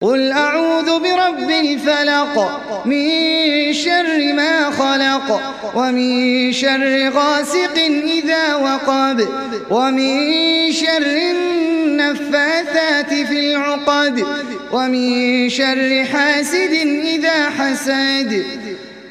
Felak Suresi İndir mp3 Ahmed Al Ajmi Riwayat Hafs an Asim, Kurani indirin ve mp3 tam doğrudan bağlantılar dinle